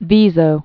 (vēzō), Mount